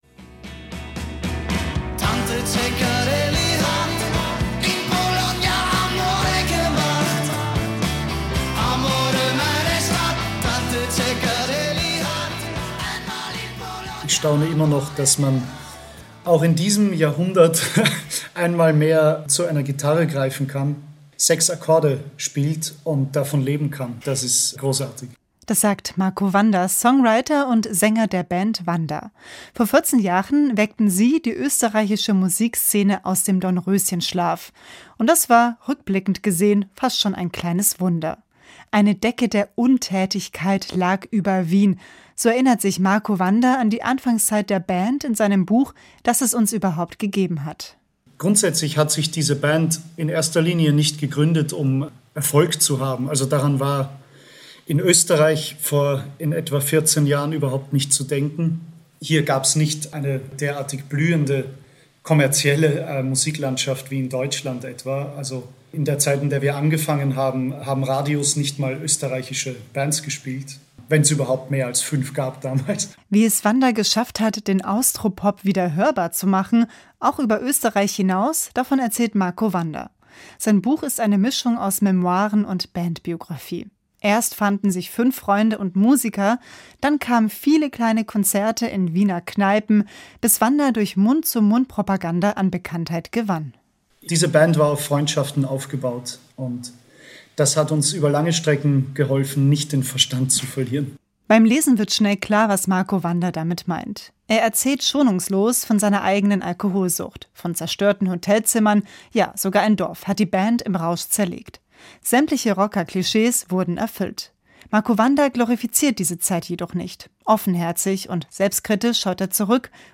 saenger-marco-wanda-ueber-sein-erstes-buch-ich-habe-versucht-jedes-gefuehl-einmal-wahrzunehmen.mp3